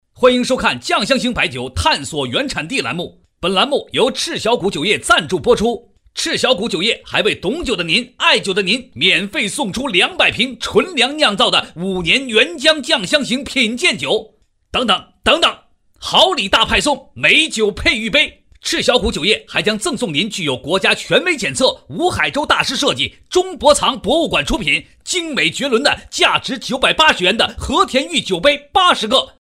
广告配音
电购男122号《探索原产地
激情力度 电视电购